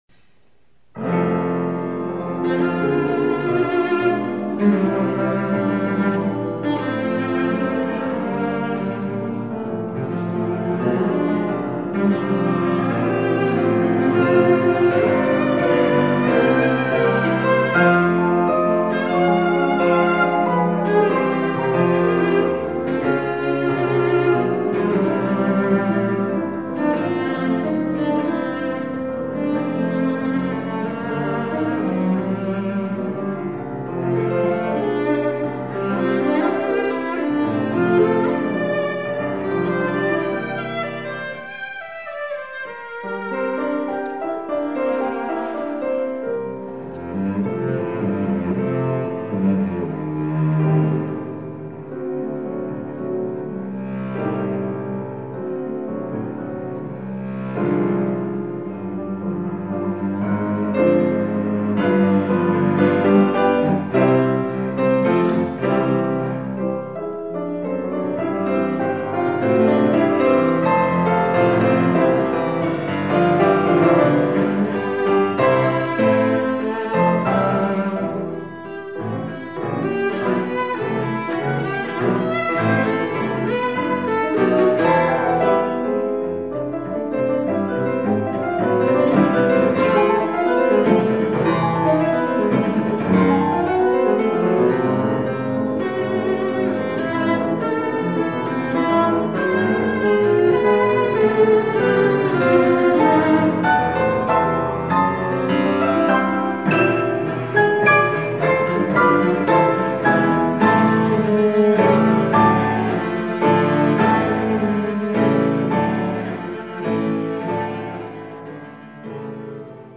Sonata para cello en F, op 99, 1er mov
Allegro vivace. 1.6 MB